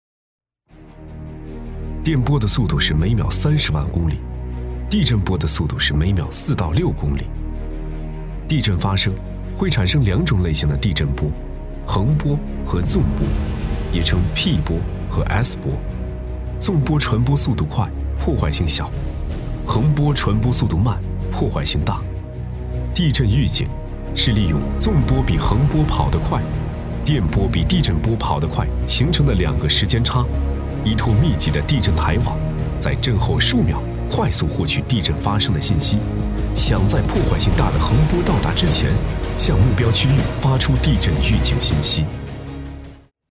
A男44号
【专题】讲解介绍 地震预警.mp3